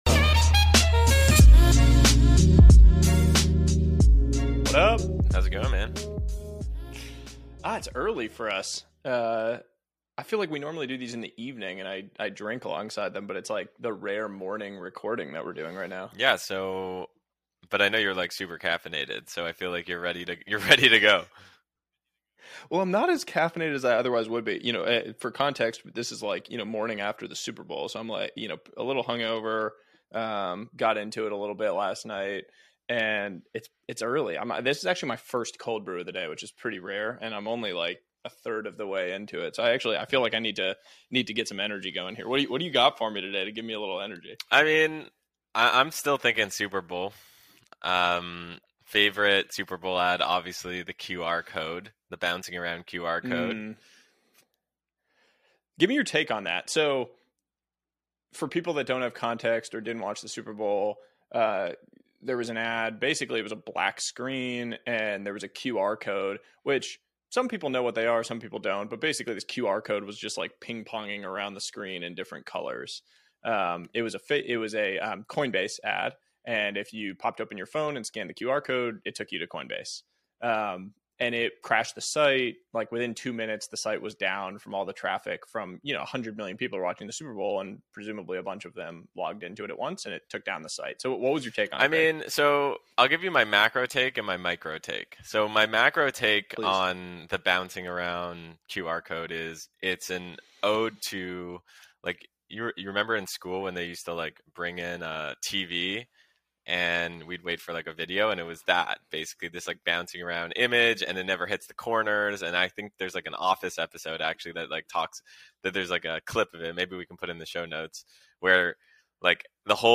public ios_share The Startup Ideas Podcast chevron_right Personality-Market Fit with Nuseir Yassin Feb 22, 2022 Guest Nuseir Yassin In this engaging conversation, Nuseir Yassin, the mind behind Nas Daily and Nas Academy, shares his journey from a Venmo employee to a YouTube sensation. He discusses the concept of 'personality-market fit' and its role in determining virality.